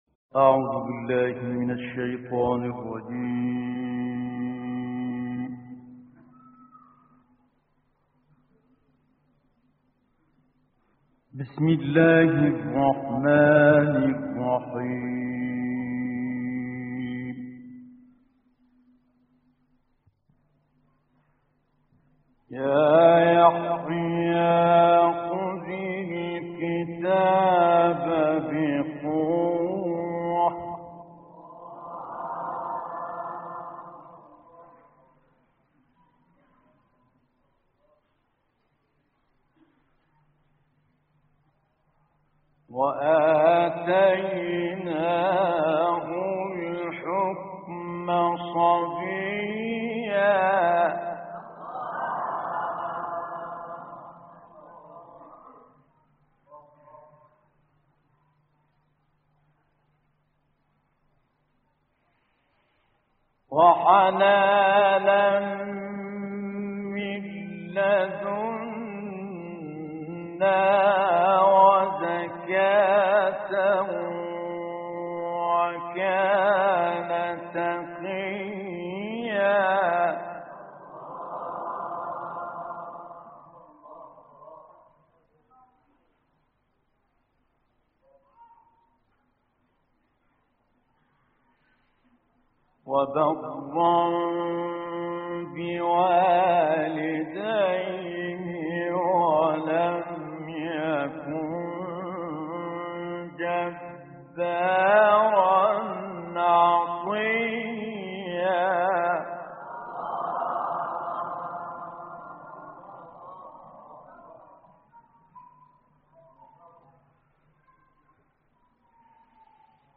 دانلود قرائت سوره مریم آیات 12 تا 36 - استاد متولی عبدالعال